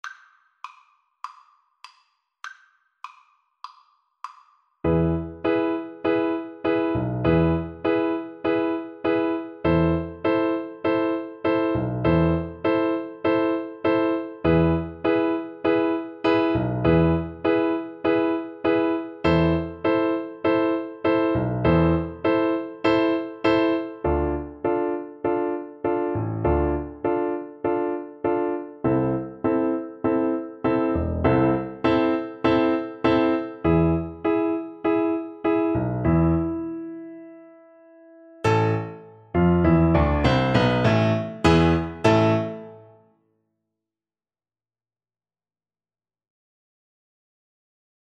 A minor (Sounding Pitch) (View more A minor Music for Tuba )
Tempo di Tango